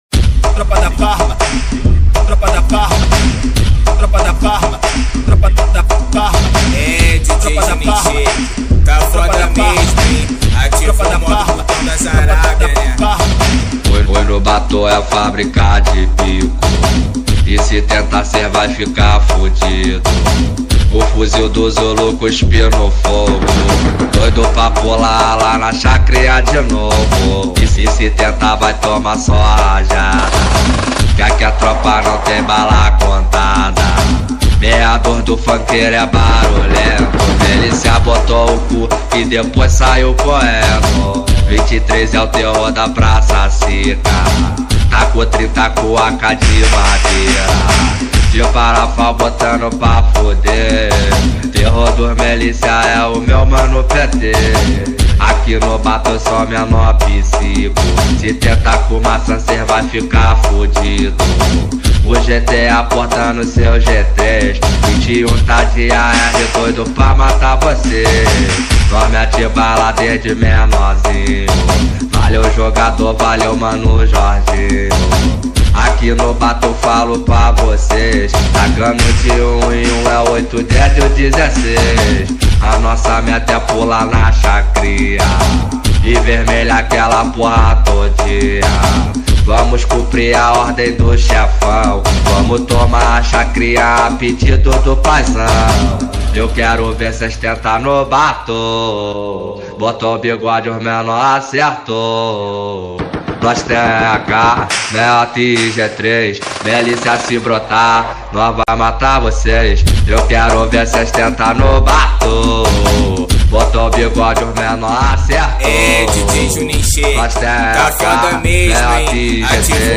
2024-02-20 11:20:35 Gênero: Trap Views